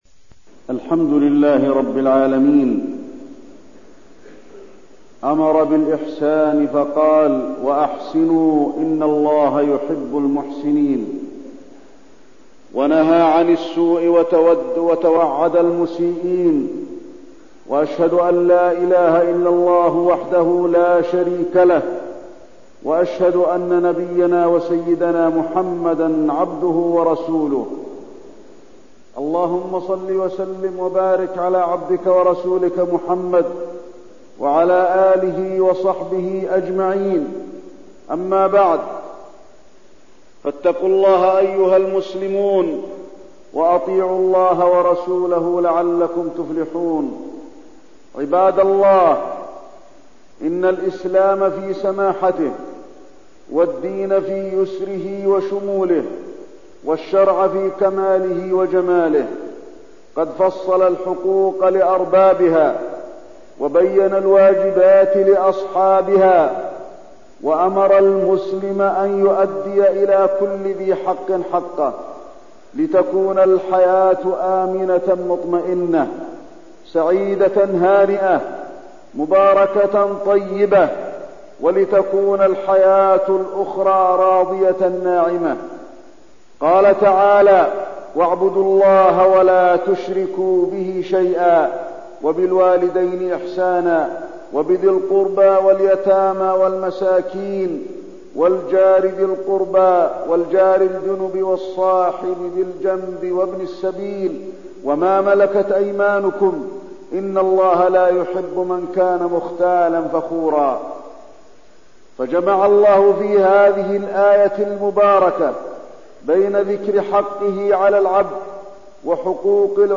تاريخ النشر ٤ صفر ١٤١٤ هـ المكان: المسجد النبوي الشيخ: فضيلة الشيخ د. علي بن عبدالرحمن الحذيفي فضيلة الشيخ د. علي بن عبدالرحمن الحذيفي حقوق الجار